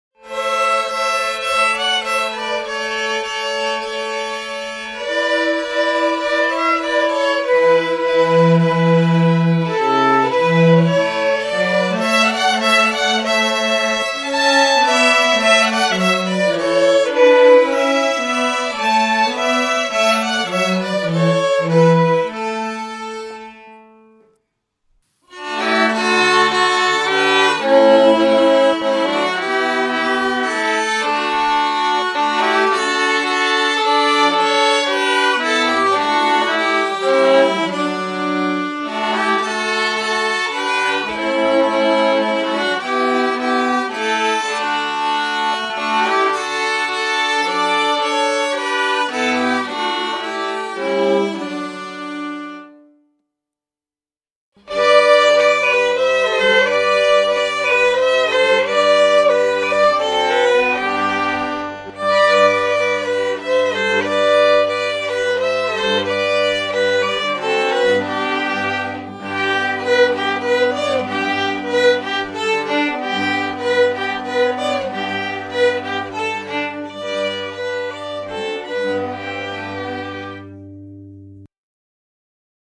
Musik-Streicher-AG-Weihnachtslieder-2020.mp3